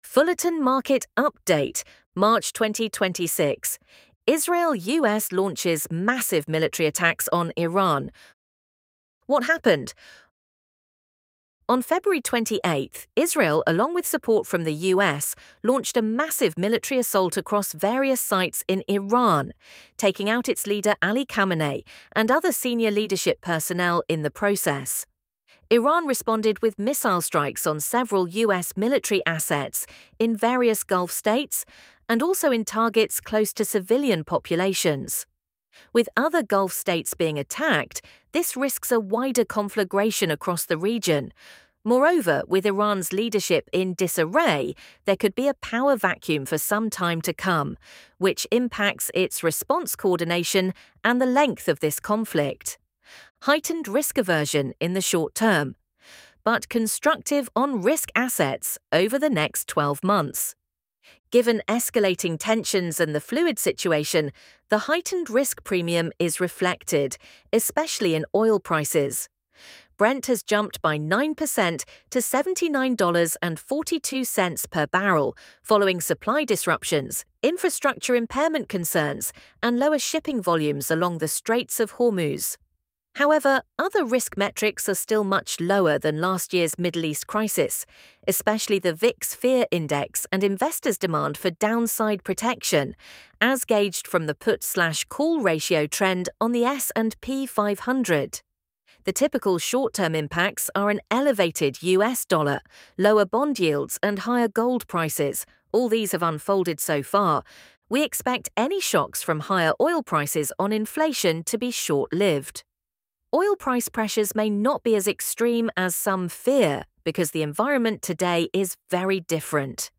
ElevenLabs_Israel-US-launches-massive-military-attacks-on-Iran.mp3